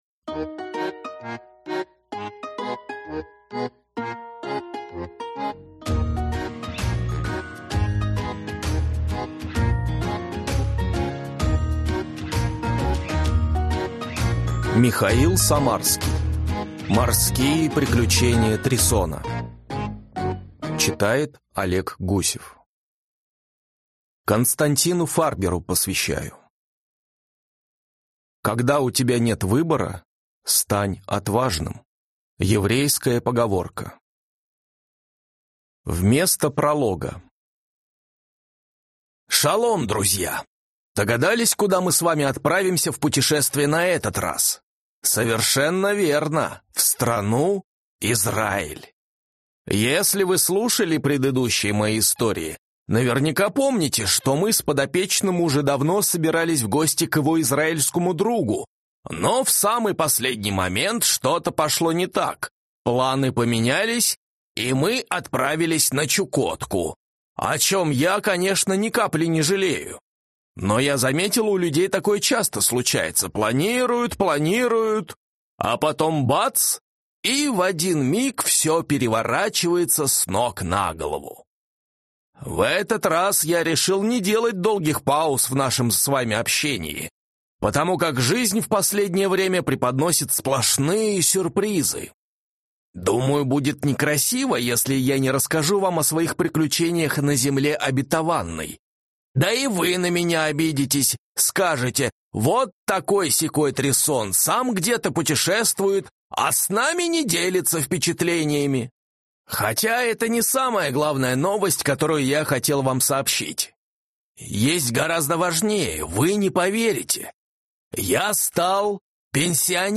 Аудиокнига Морские приключения Трисона | Библиотека аудиокниг